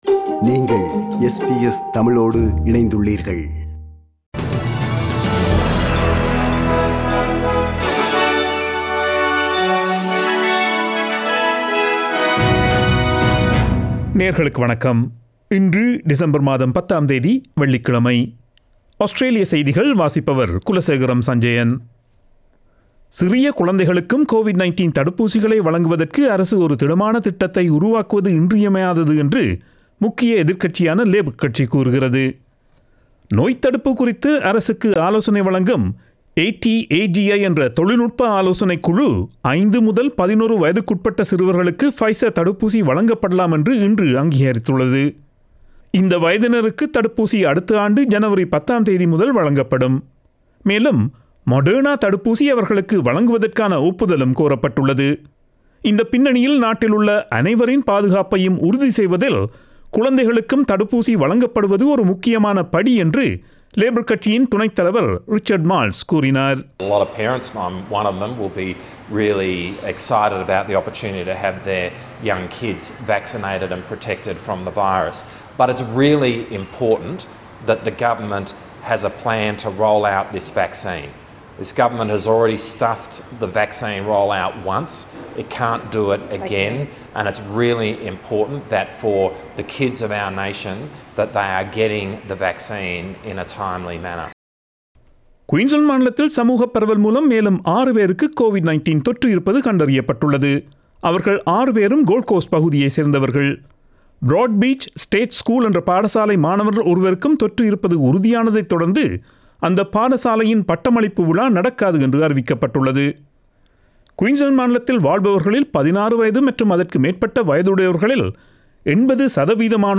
Australian news bulletin for Friday 10 December 2021.